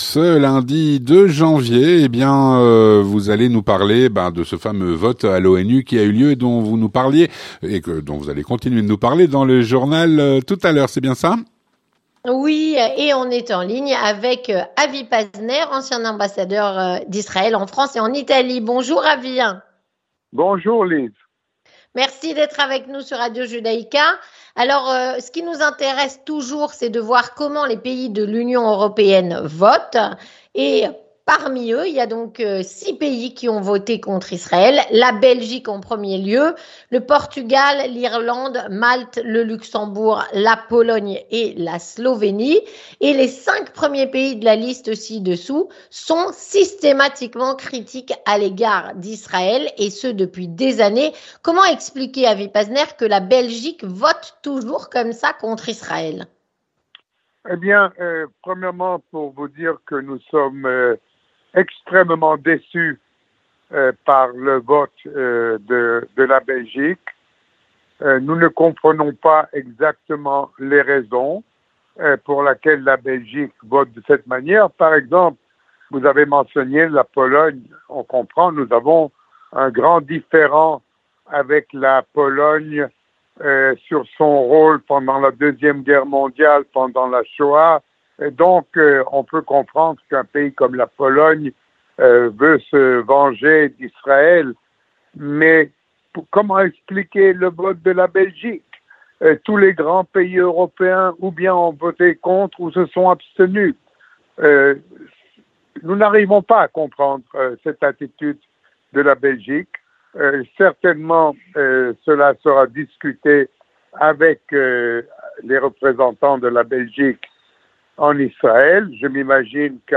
A la Une en Israël - avec Avi Pazner, ancien ambassadeur d’Israël en France et en Italie et Yehuda Lancry, ancien ambassadeur d’Israël en France et à l’ONU